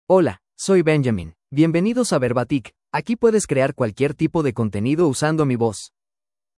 MaleSpanish (United States)
Voice sample
Male
Spanish (United States)